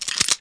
Index of /server/sound/weapons/9mm_smg
wpn_9mm_reload.wav